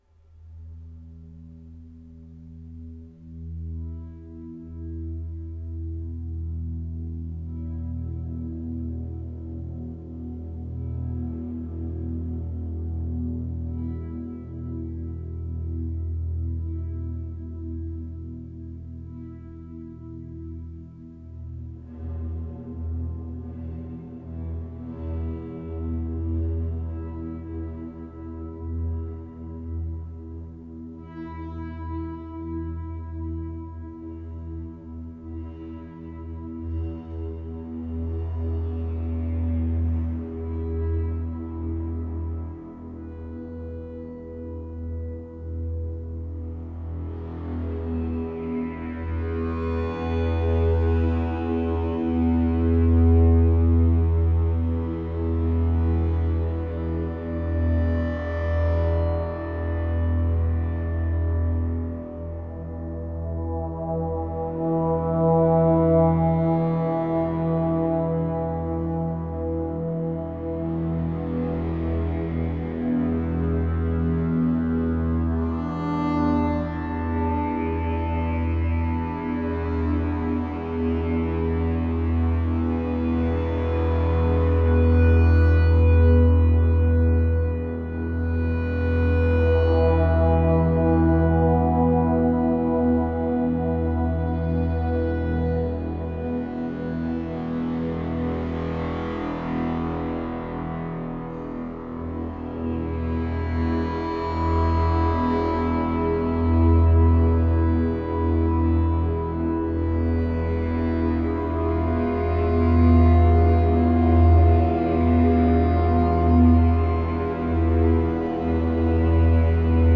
Recording by Pauline Oliveros in the underground "Cistern", Fort Worden, Washington with 45 sec. reverb time